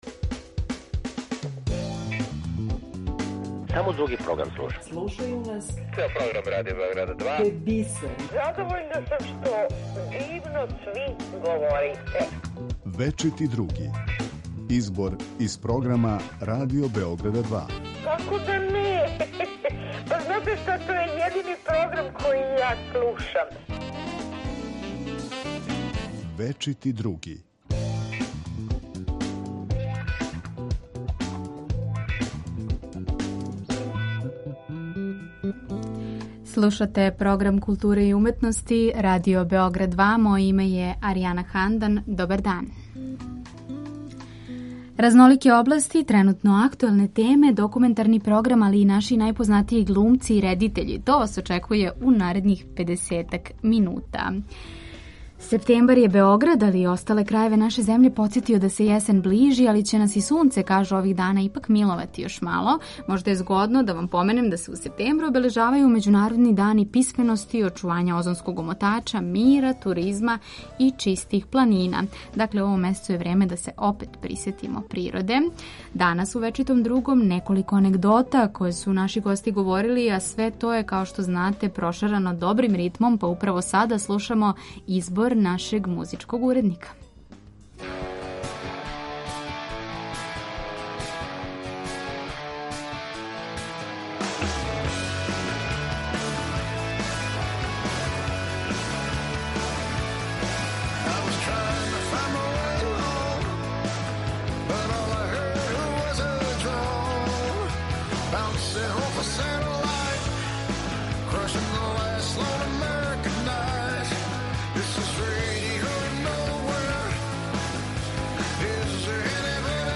Један од наших најзначајнијих позоришних редитеља, Егон Савин, присетиће се свог доласка у Београд, говориће о позоришној режији некада и глумцима данас.
Слушаћемо једног од наших најзначајнијих позоришних, филмских и телевизијских глумаца, Ирфана Менсура, који ће се присетити анегдоте о својим професионалним почецима у Југословенском драмском позоришту.
Чућемо шта су слушаоци Радио Београда 2 говорили о томе да ли ће ЛГБТ заједница ипак одустати од еуропрајда у Београду. У делу репортаже који ћемо емитовати говориће мотоциклиста који користи сваки леп дан и слободан тренутак како би сео на мотор и кренуо у истраживање забачених и неоткривених делова Србије.